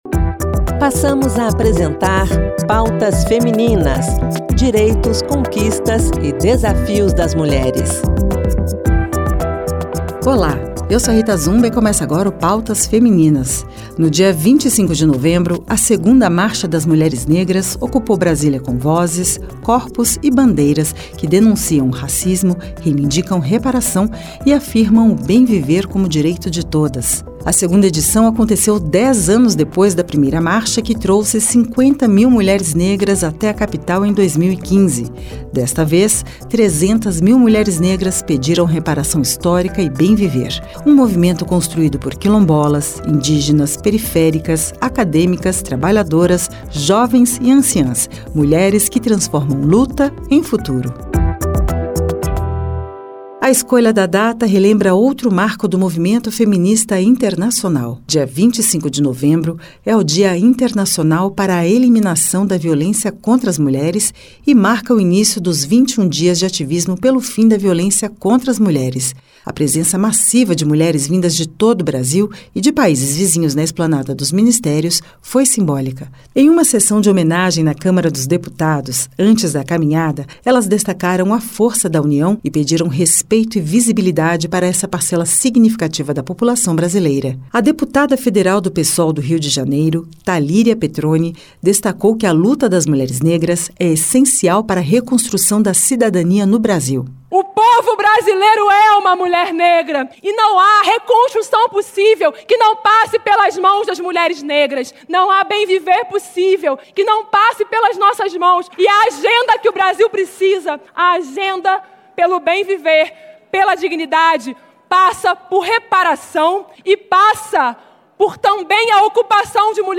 O programa traz trechos das falas de parlamentares, ministras e lideranças do movimento, entre elas Talíria Petrone, Margareth Menezes, Márcia Lopes, Anielle Franco e Benedita da Silva.